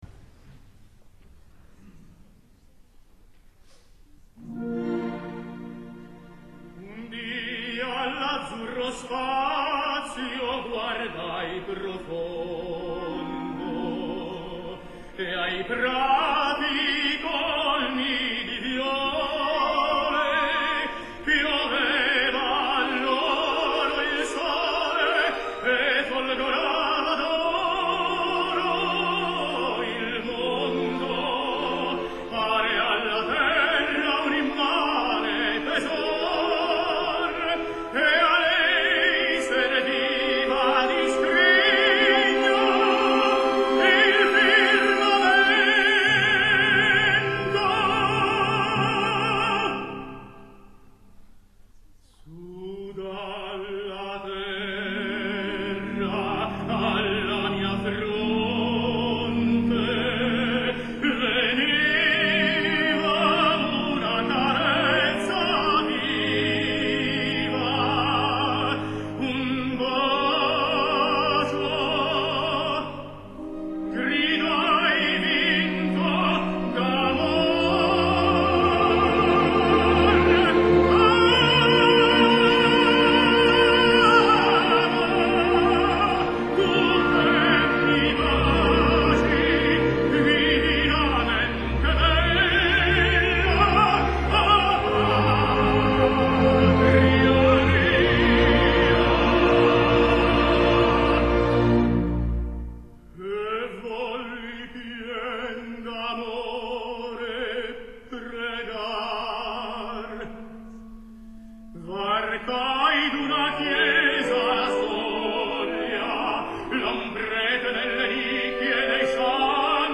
Roberto Alagna, tenor
Recital in Aarhus (Danemark)
Aarhus Symphony Orchestra